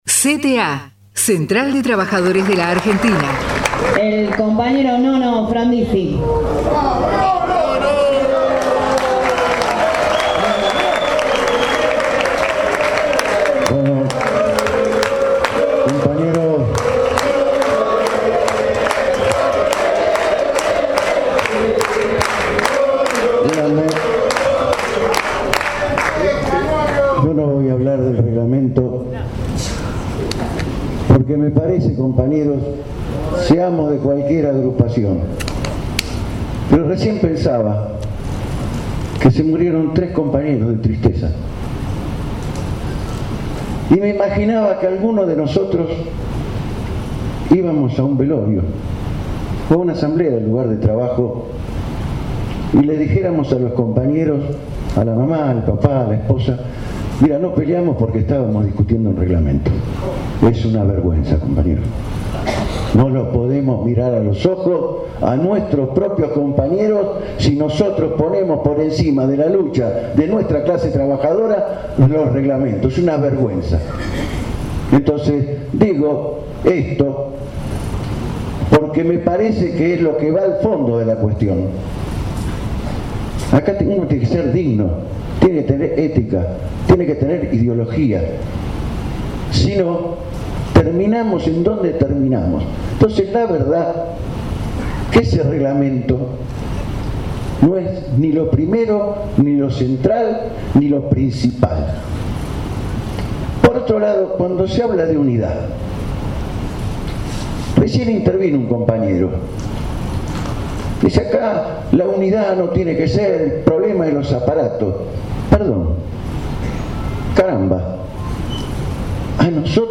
Plenario General de Delegados ATE CAPITAL